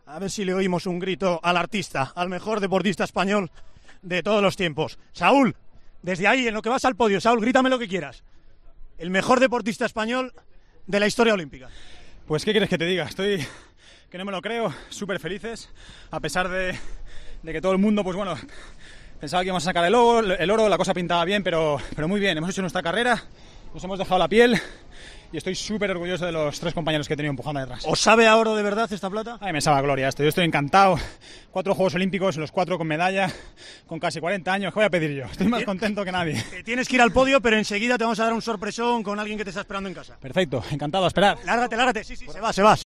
El palista español ha conseguido su quinto metal en los Juegos al sumar la plata en el K1 500 y sus primeras palabras en COPE han sido: "Muy orgulloso de mis tres compañeros".